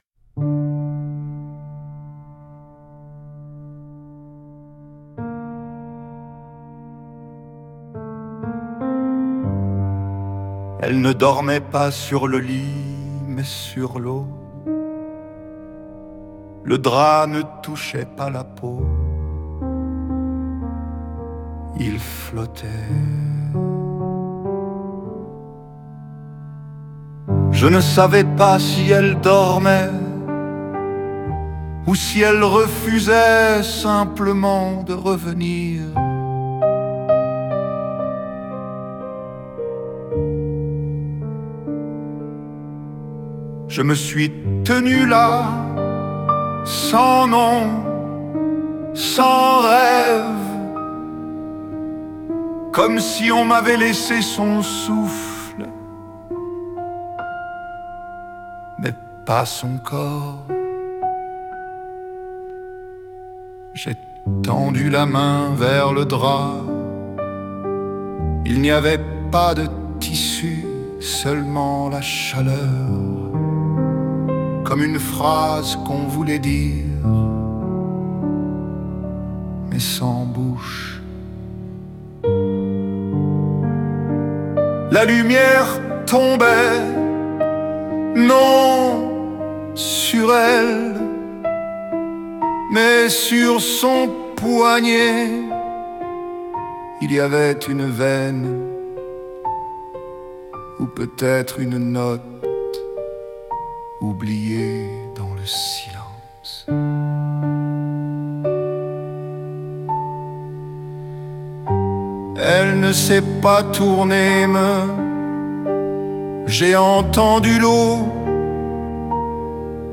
somatopoème